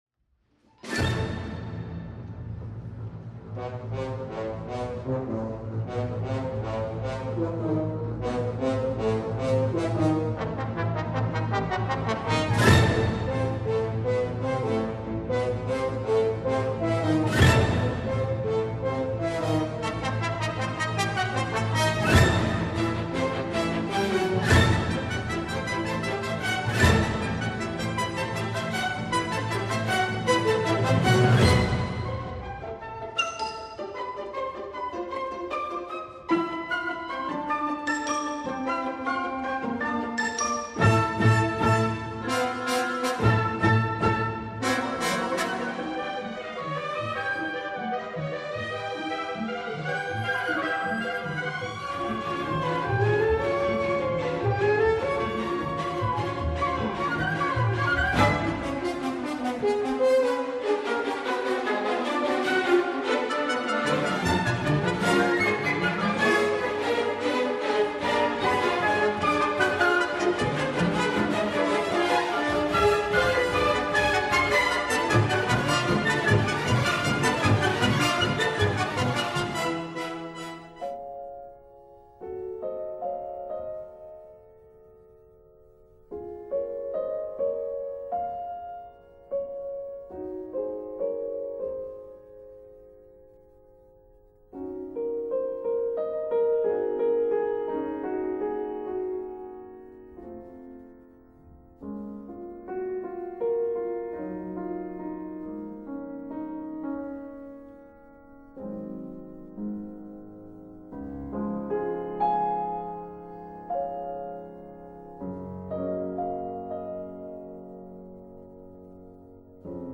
5. Para el descanso: ESCUCHAR LA SIGUIENTE AUDICIÓN en un ambiente relajado, bajar las luces y acomodarse e identificar qué emoción le transmite cada fragmento de música.
MEZCLA_FRAGMENTOS_TRABAJO_EMOCIONES.mp3